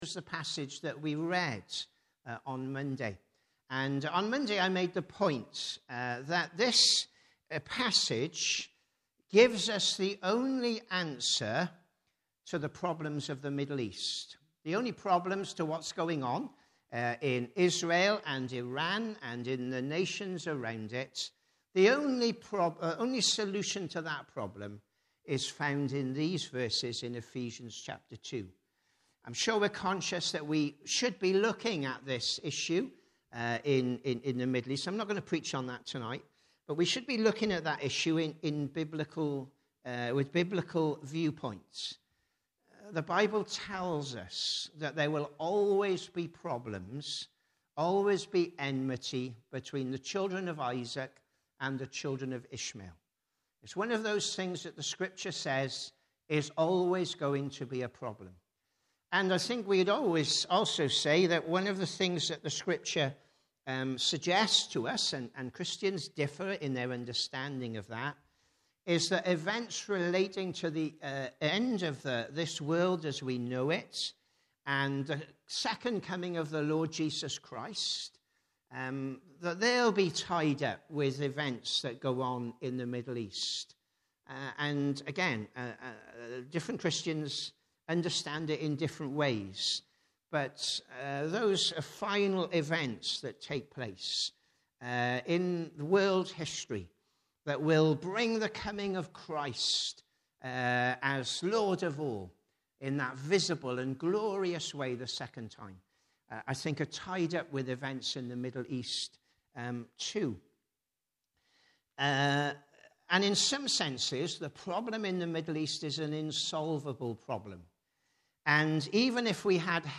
Ephesians 2:1-22 Congregation: PM Service « If I become a Christian